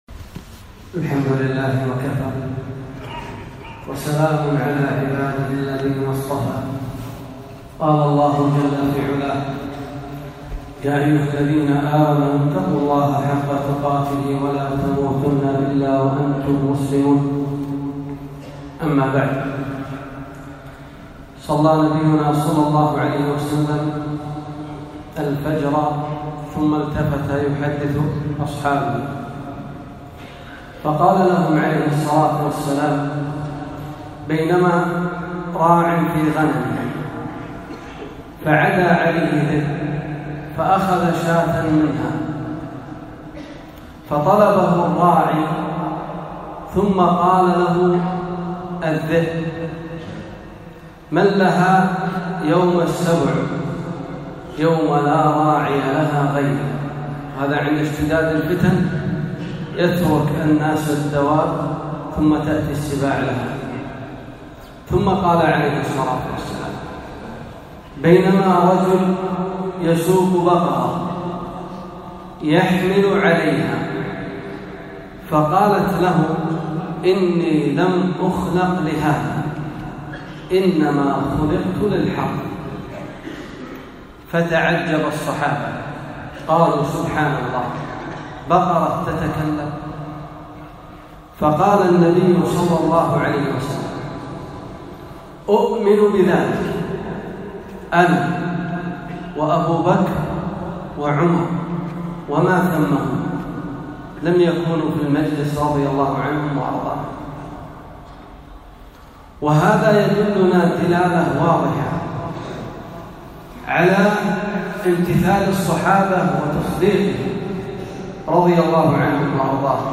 خطبة - سرعة إستجابة الصحابة رضي الله عنهم